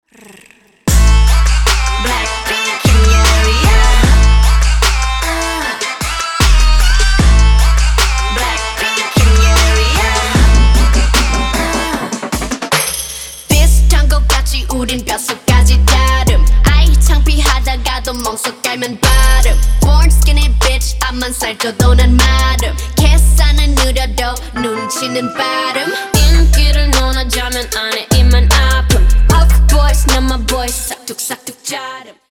бесплатный рингтон в виде самого яркого фрагмента из песни
Поп Музыка